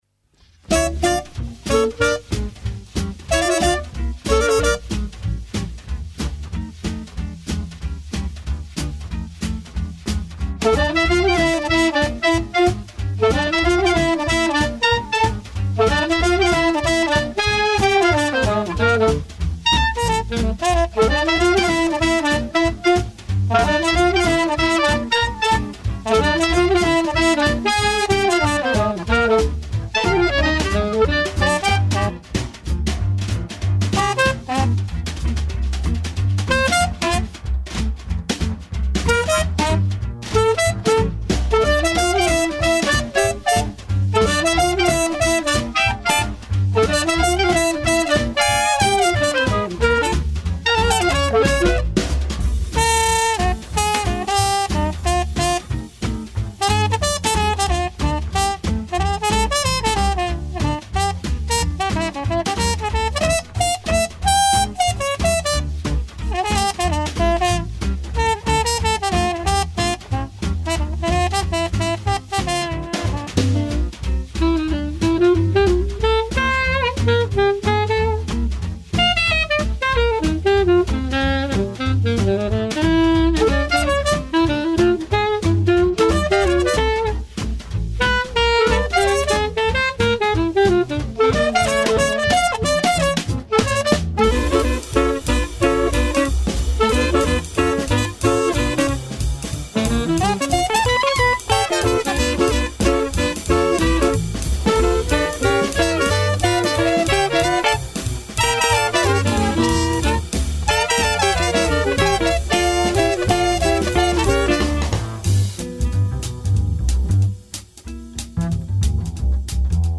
Délicieusement vintage